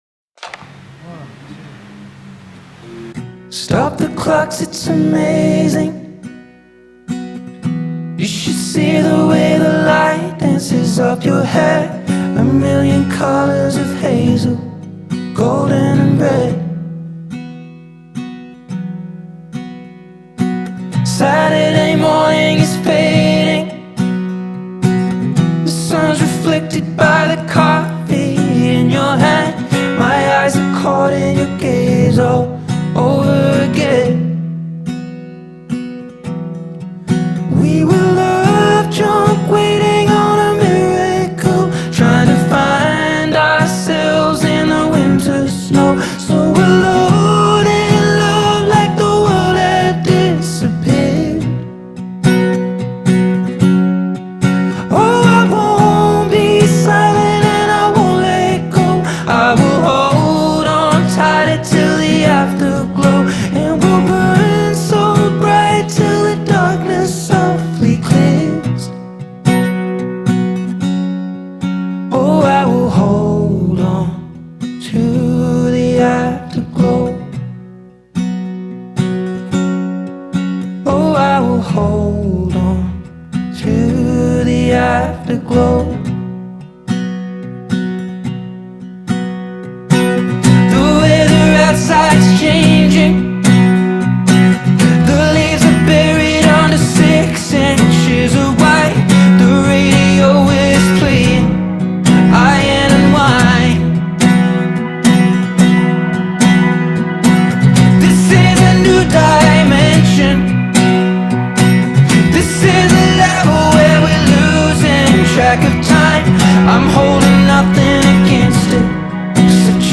Folk pop